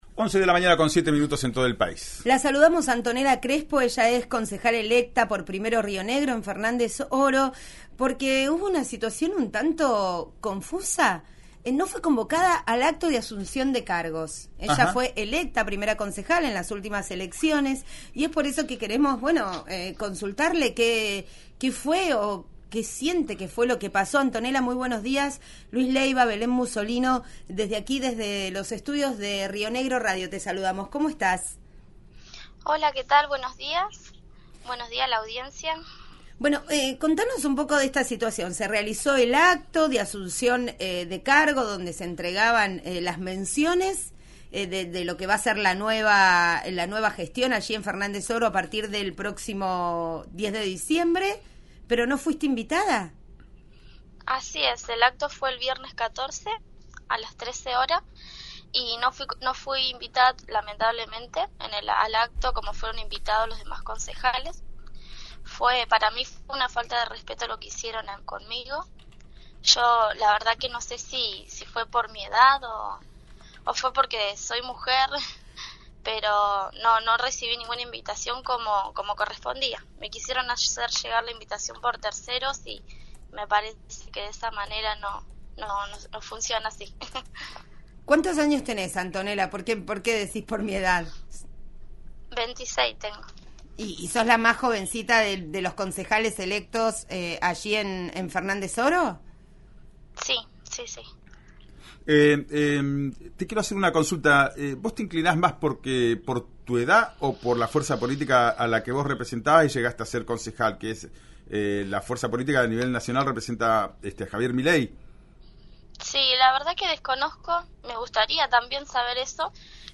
Escuchá a la concejala electa Antonella Crespo de Fernández Oro en “Ya es Tiempo”, por RÍO NEGRO RADIO: